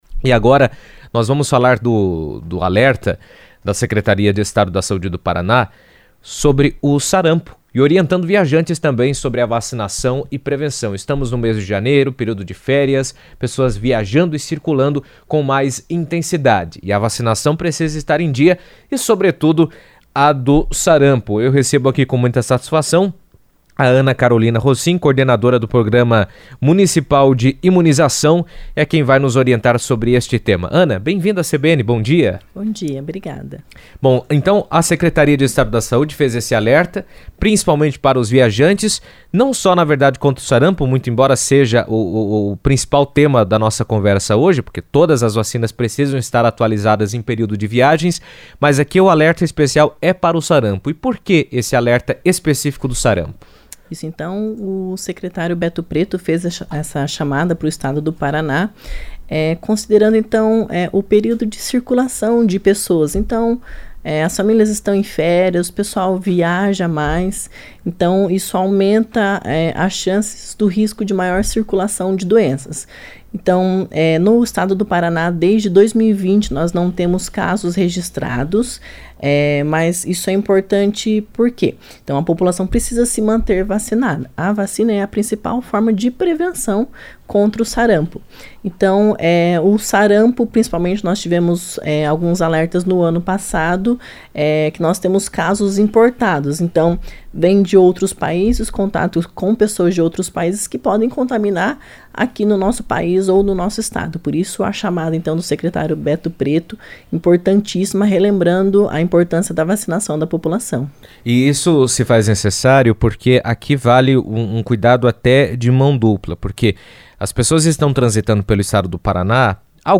entrevista à rádio CBN